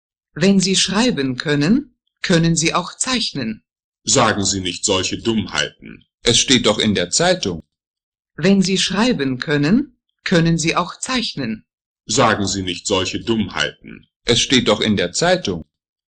Since you’re not concerned with preserving stereo imaging I applied mda-De Esser settings (see screenshot) to my edits applied to the vocal sample from that other thread I linked.